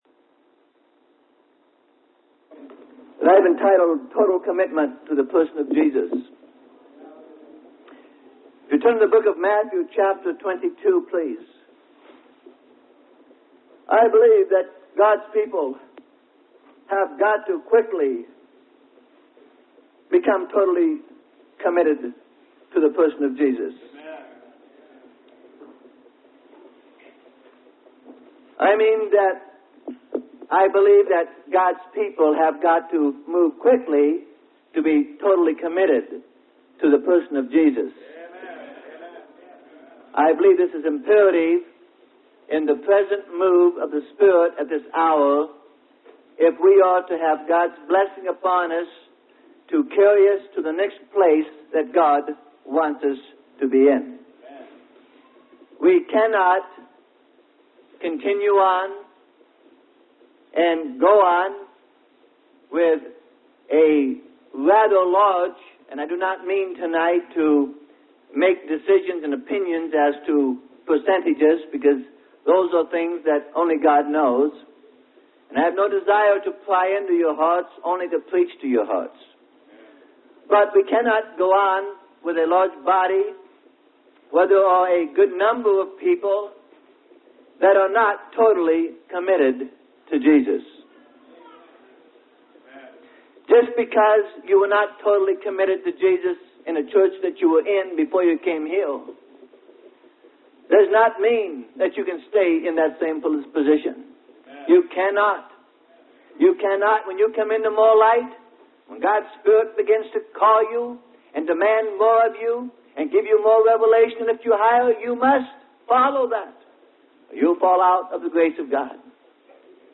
Sermon: Totally Committed To The Person Of Jesus. - Freely Given Online Library